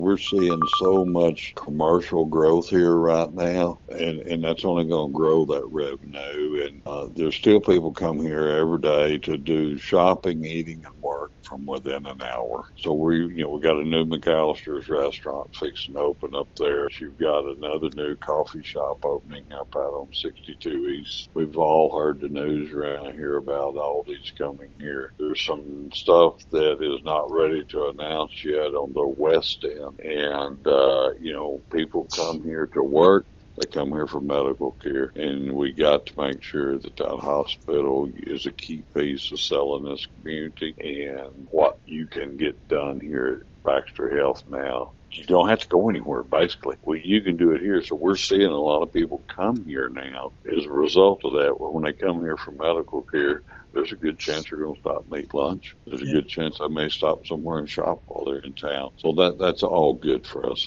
Following the event, Mayor Hillrey Adams shared key takeaways in an interview with KTLO, Classic Hits and The Boot News.